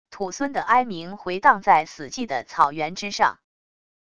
土狲的哀鸣回荡在死寂的草原之上wav音频